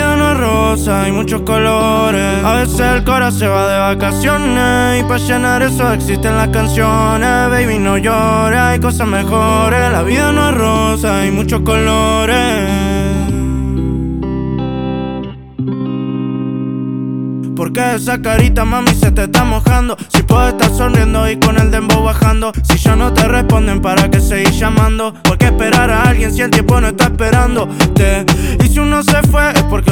Жанр: Латиноамериканская музыка / Рэп и хип-хоп / Русские
# Latin Rap